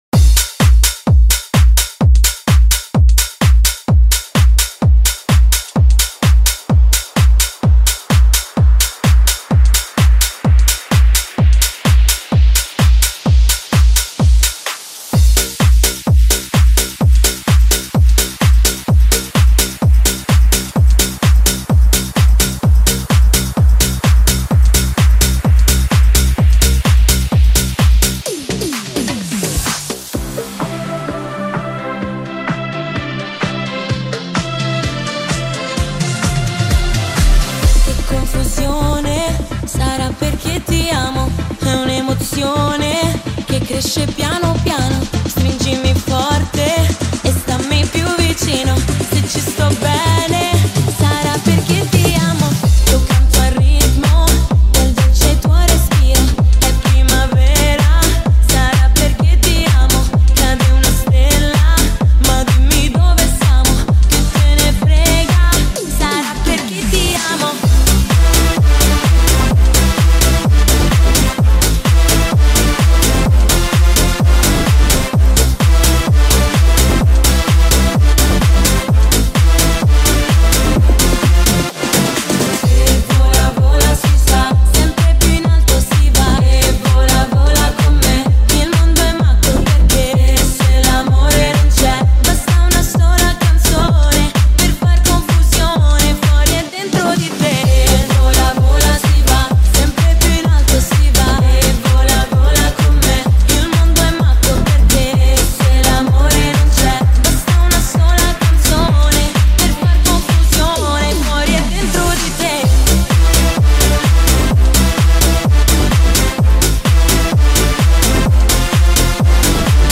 dj club cover remix